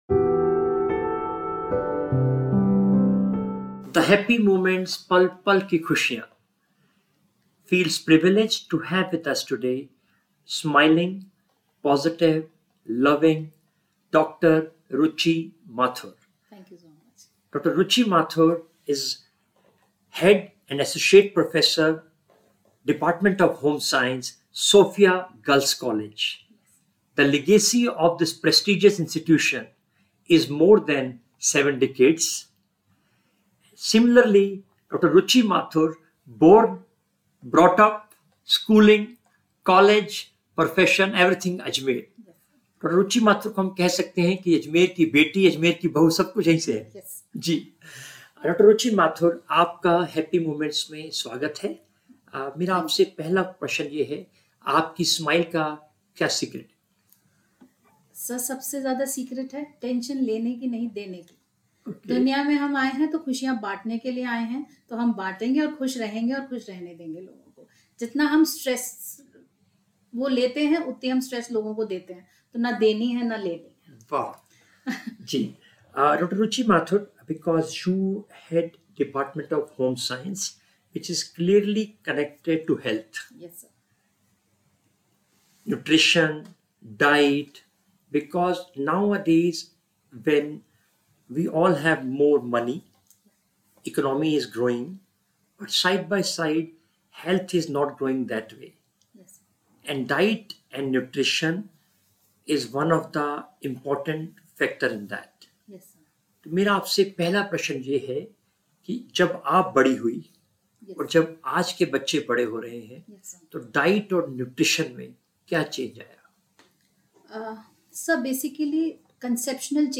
Join us for an inspiring conversation that promises to leave you with practical advice and a renewed appreciation for the importance of nutrition in shaping a brighter future.